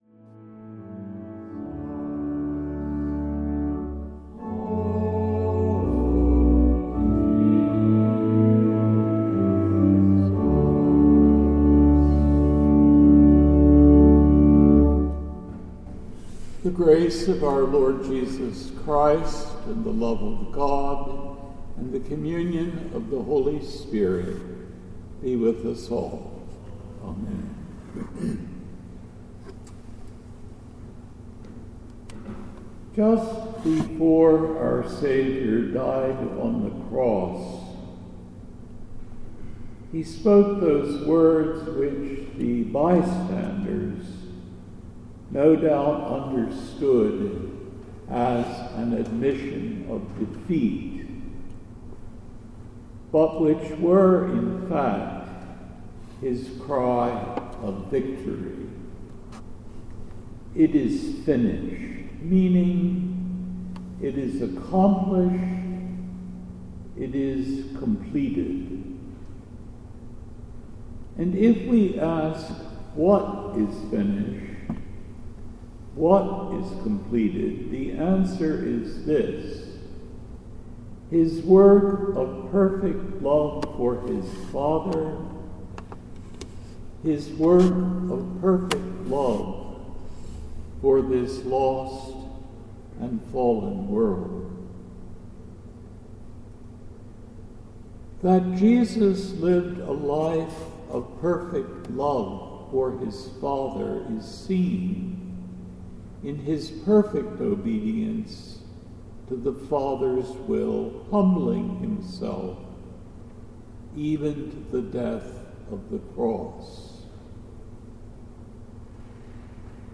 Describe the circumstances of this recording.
Reminiscere Midweek Vespers. Reminiscere – The Second Sunday in Lent.